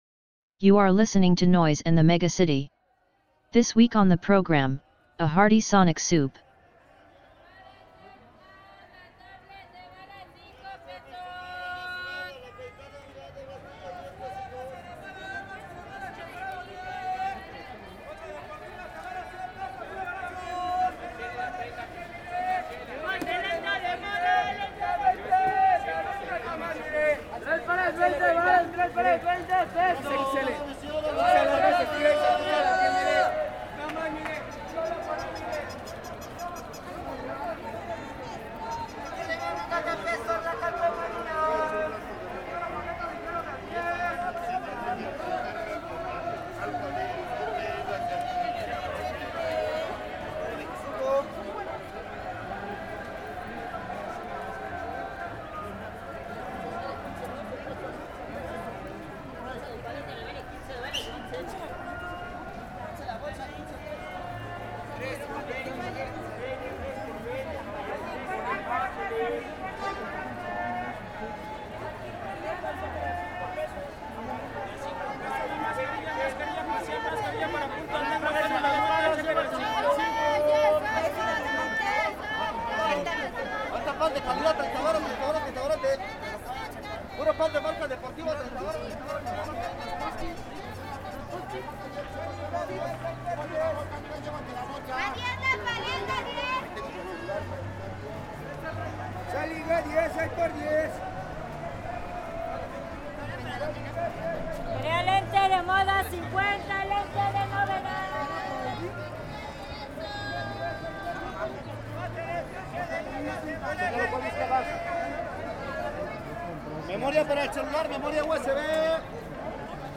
A dive into an archive of collected noises from 10 megacities across the globe. Interviews and ruminations will be mixed in with these sounds, in search of a better understanding of a fundamental question: "what is noise?